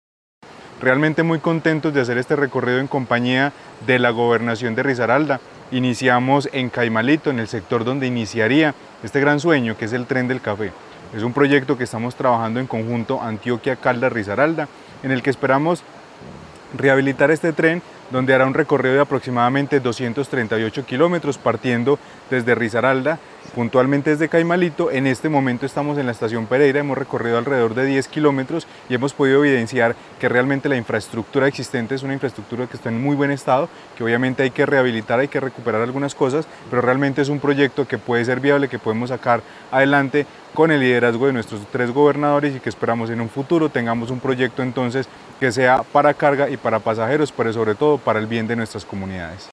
Carlos Anderson García, secretario de Planeación de Caldas